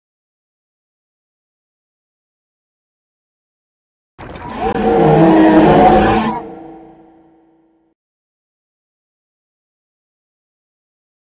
File:GCNO1 Roar.ogg